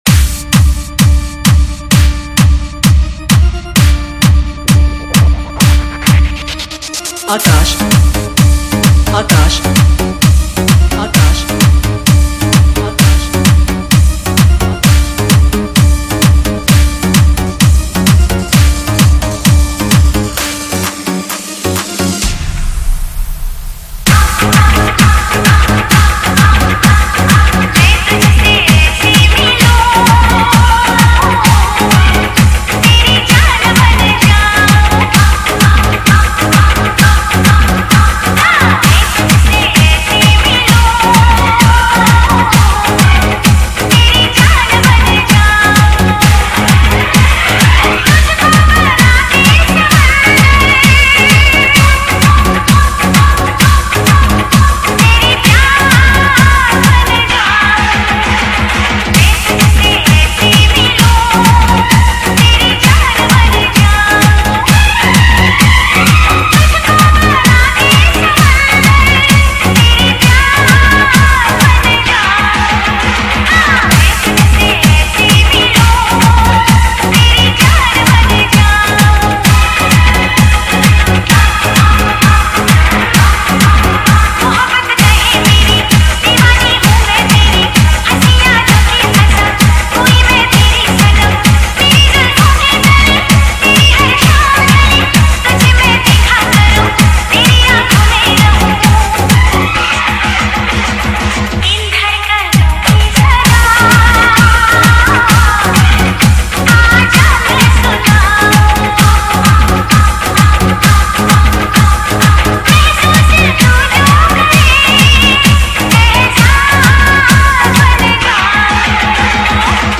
Hindi Old Is Gold Mix Single Song